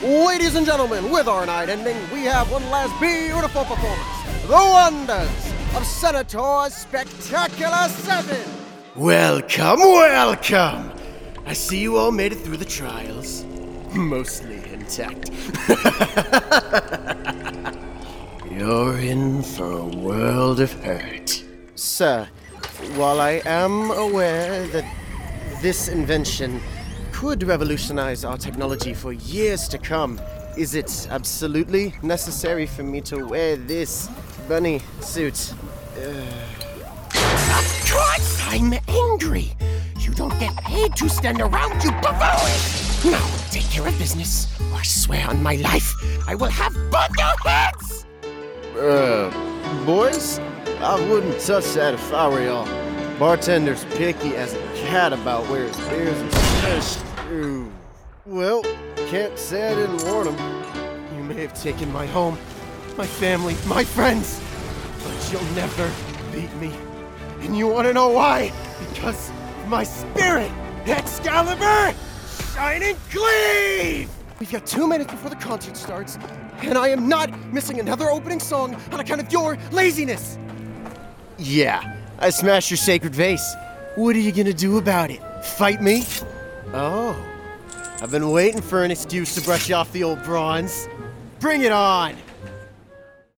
Demo Reels